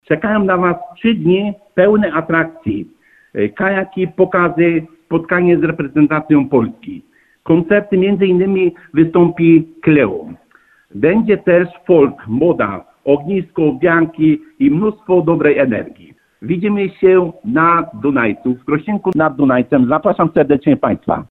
-Impreza startuje już czwartek (19.06) w Krościenku nad Dunajcem – zaprasza wójt tego samorządu, Stanisław Tkaczyk.